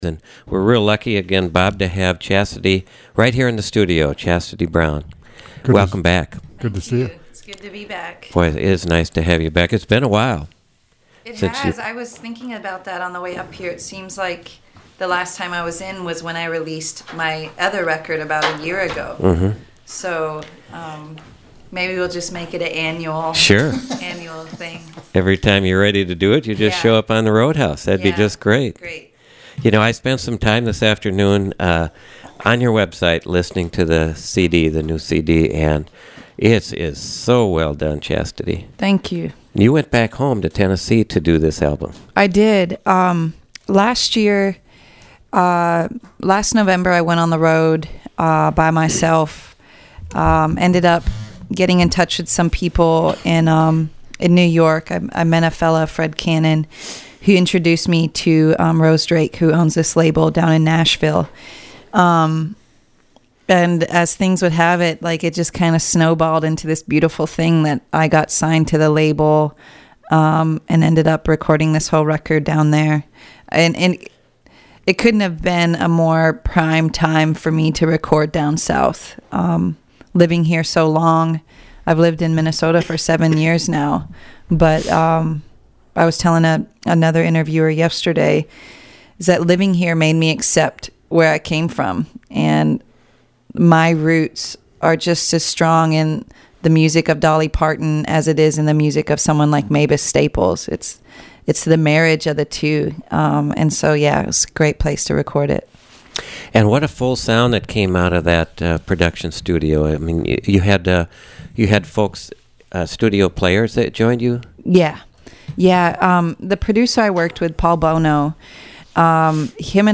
Live Music Archive